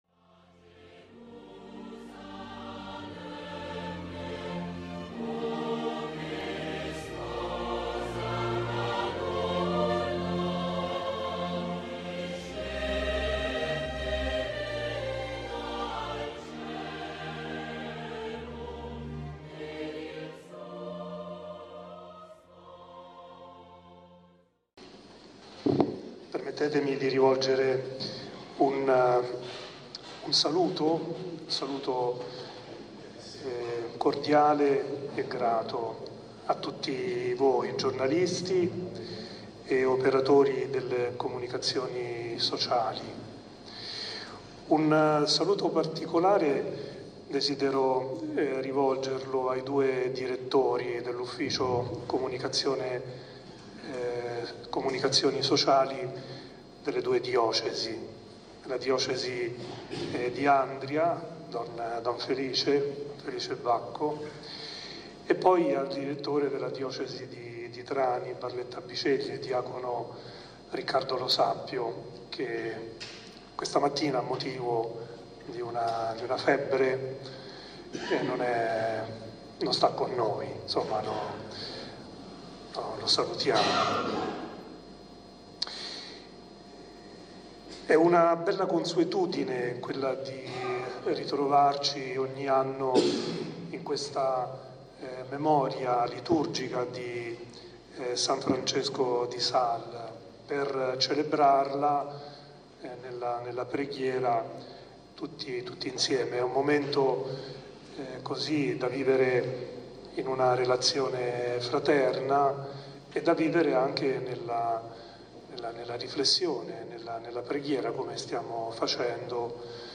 Trani – Santa Messa in occasione della Festa di San Francesco di Sales
La celebrazione è presieduta da Mons. Leonardo D'Ascenzo, Arcivescovo di Trani-Barletta-Bisceglie.
omelia.mp3